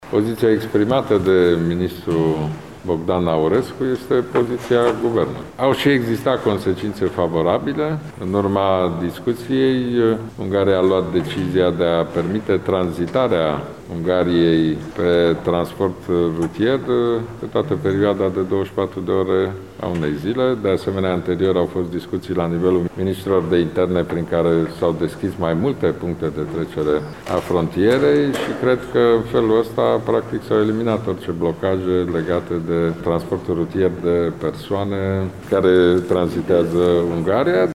Declarația a fost făcută astăzi de premierul Ludovic Orban, aflat în vizită la Tîrgu Mureș: